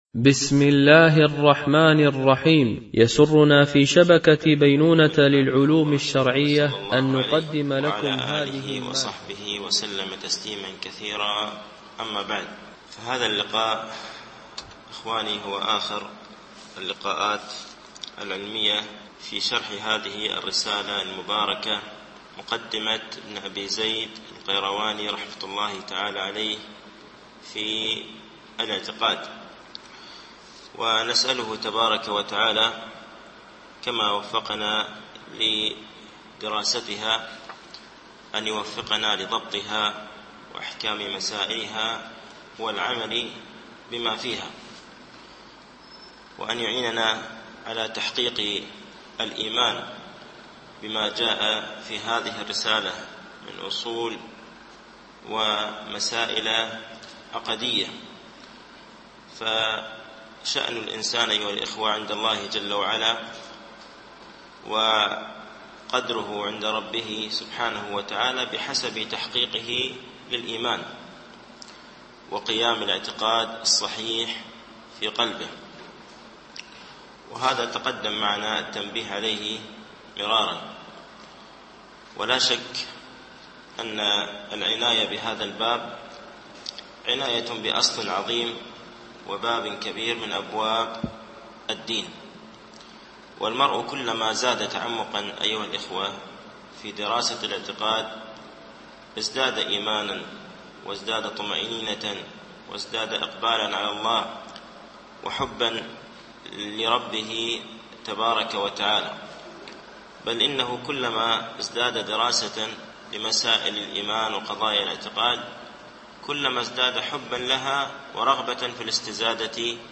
شرح مقدمة ابن أبي زيد القيرواني ـ الدرس السابع و الثمانون